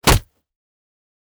Punch Impact (Flesh) 2.wav